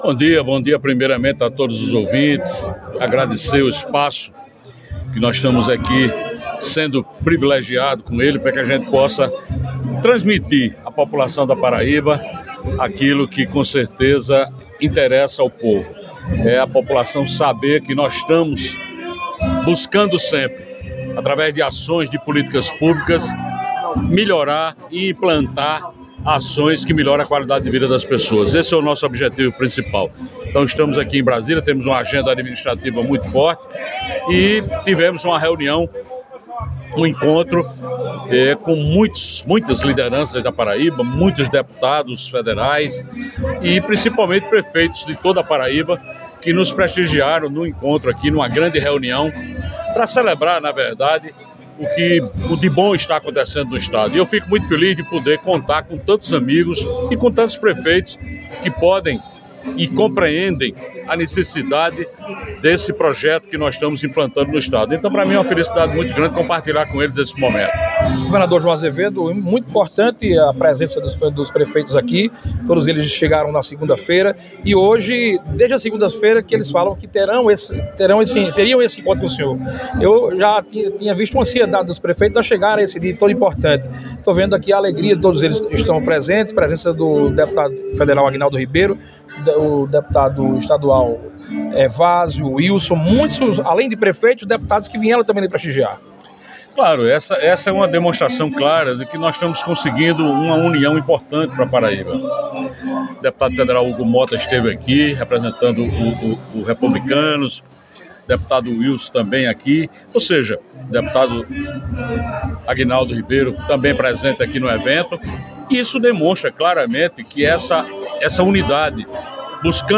João Azevedo participa da CNM, e de Brasília dá entrevista ao Nordeste1 - Nordeste 1